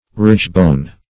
Search Result for " ridgebone" : The Collaborative International Dictionary of English v.0.48: Ridgebone \Ridge"bone`\, n. The backbone.